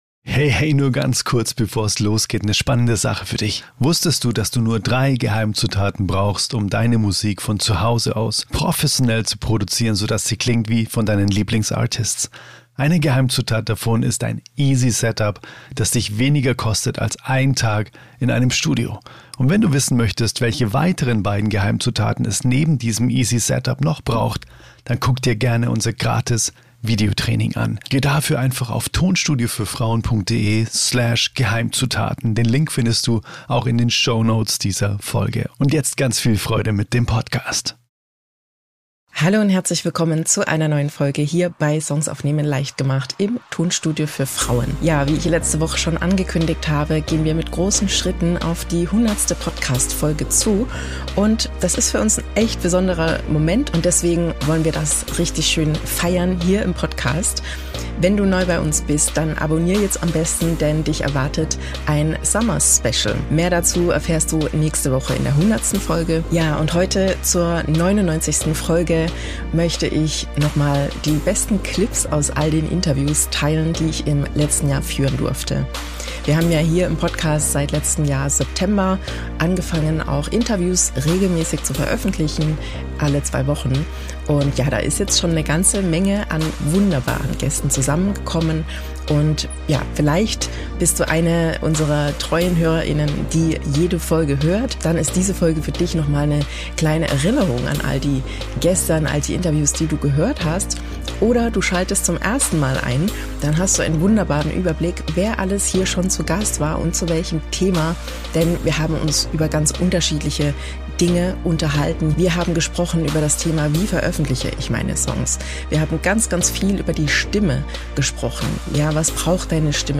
Best of Interviews – Besondere Momente aus den letzten 12 Monaten